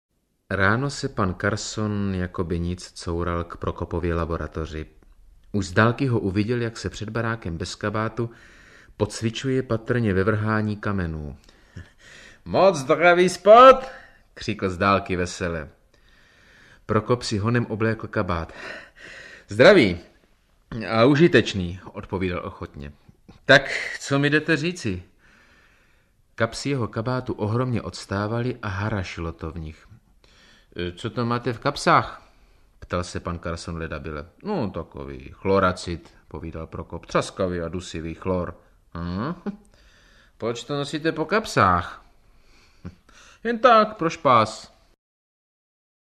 Audio kniha
Ukázka z knihy
• InterpretLuděk Munzar